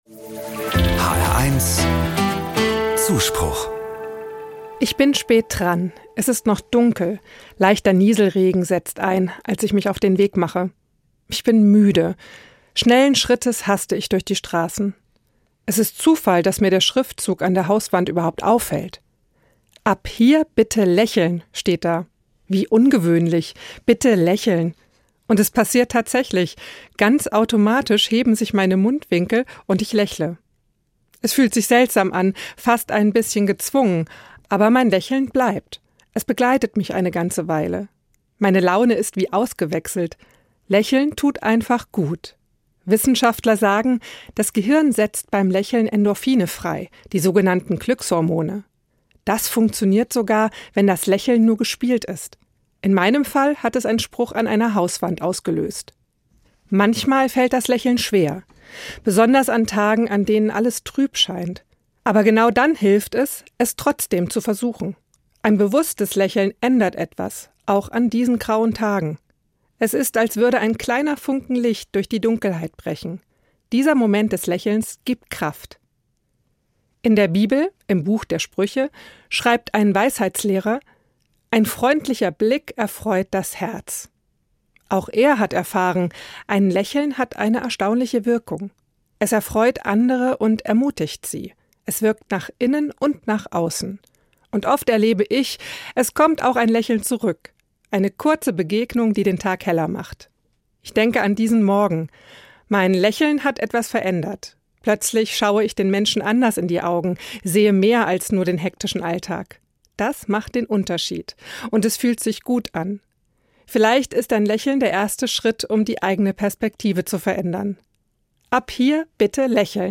1 Männer-Achtsamkeit im neuen Jahr | Comedy 2:13 Play Pause 6d ago 2:13 Play Pause Nghe Sau Nghe Sau Danh sách Thích Đã thích 2:13 Männer müssen, gerade wenn sie älter sind, auf sich achten - das gilt für die inneren und die äußeren Werte.